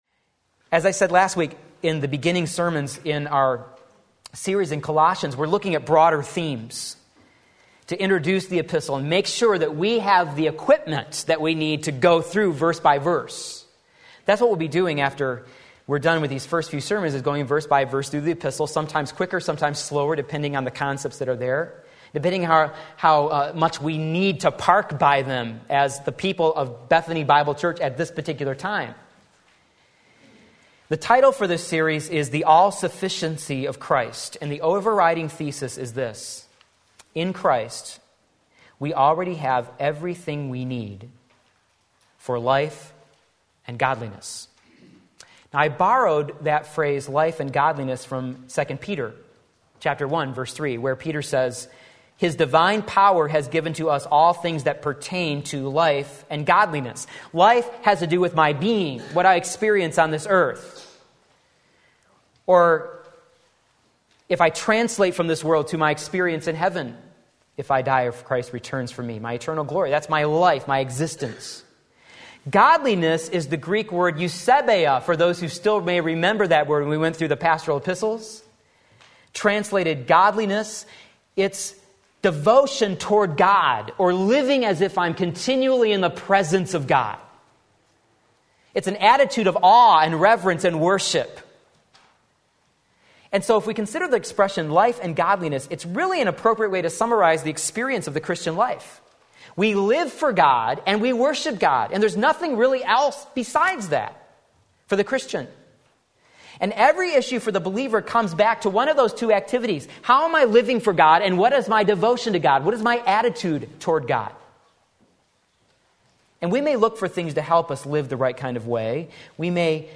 The Mystery Colossians 1:26-27 Sunday Morning Service